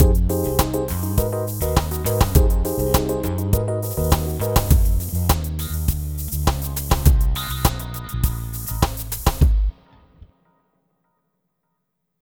Ala Brzl 1 Fnky Full-F.wav